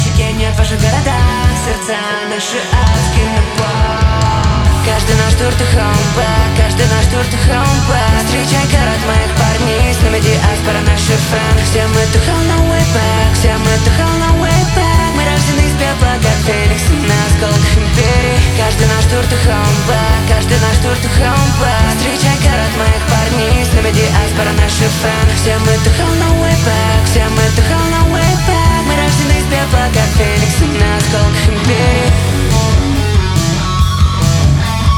Жанр: Русская поп-музыка / Поп / Рок / Русский рок / Русские